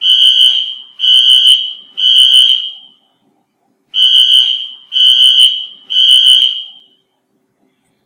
alarm.ogg